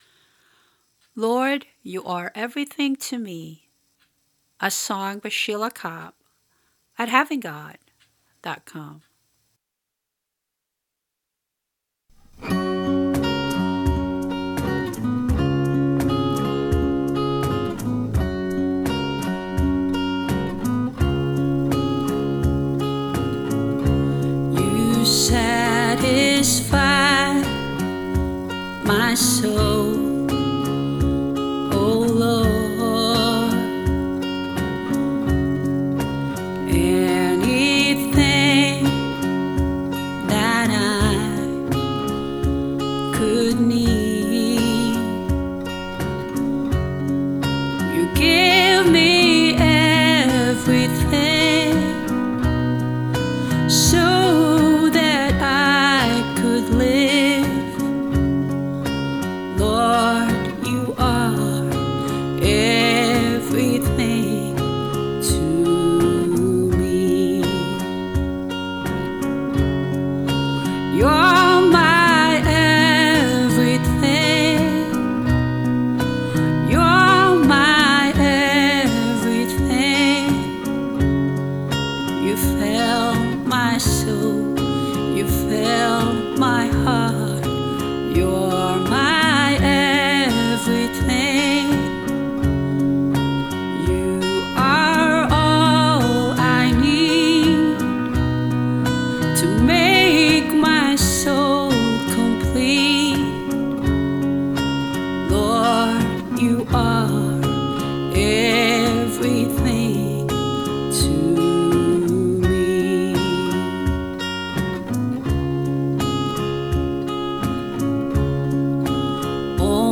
Vocals, guitar, bass and drum machine
Organ, Keyboard and strings